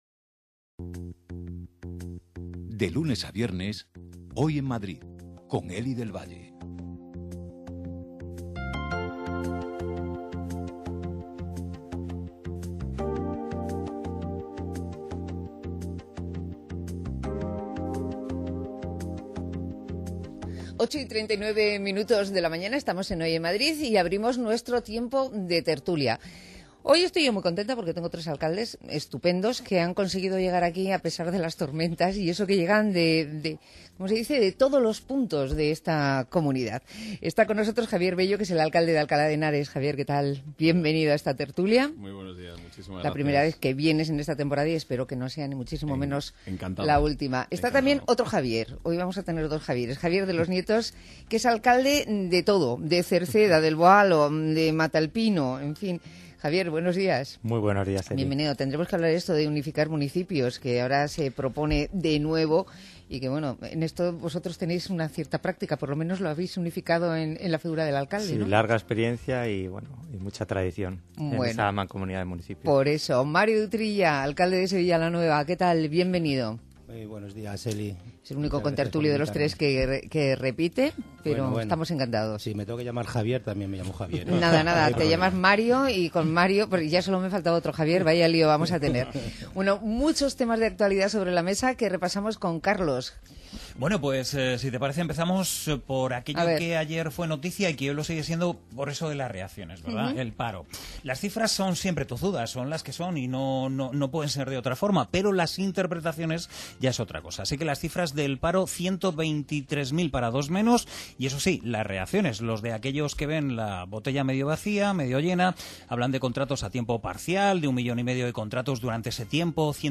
Tertulia de alcaldes